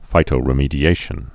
(fītō-rĭ-mēdē-āshən)